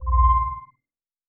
Click (19).wav